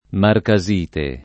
vai all'elenco alfabetico delle voci ingrandisci il carattere 100% rimpicciolisci il carattere stampa invia tramite posta elettronica codividi su Facebook marcasite [ marka @& te ] o marcassite [ marka SS& te ] s. f. (min.)